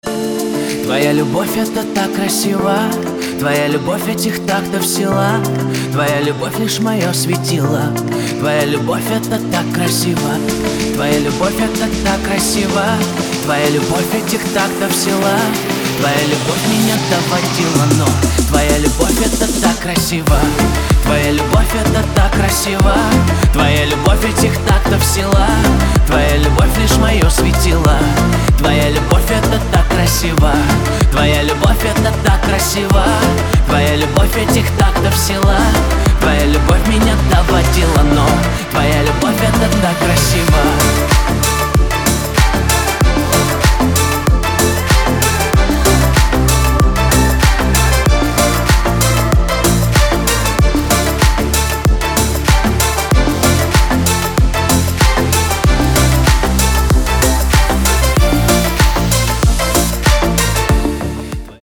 • Качество: 256, Stereo
мужской вокал
dance
club